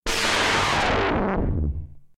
KART_turboStart.ogg